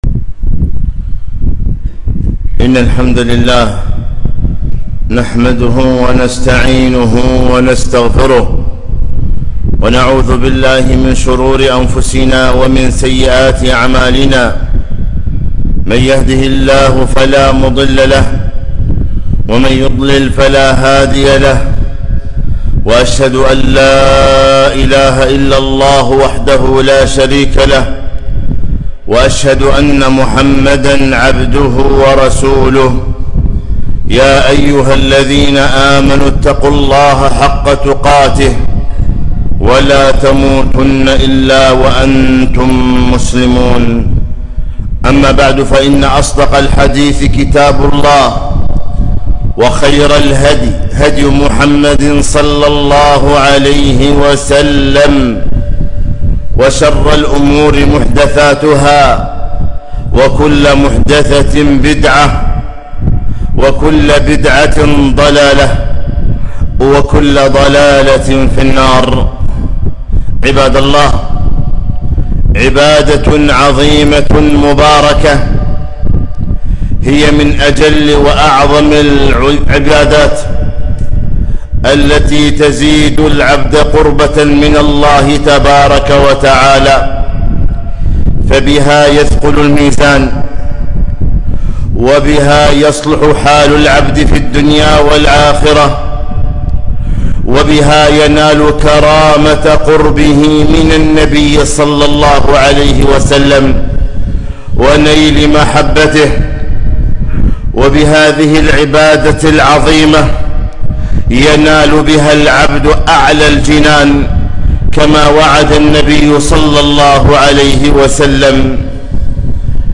خطبة - (الأخلاق الحسنة سعادة في الدنيا والآخرة)